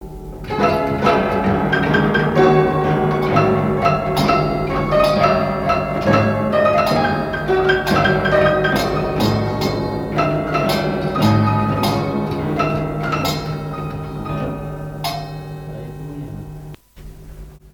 piano mécanique
Pièce musicale inédite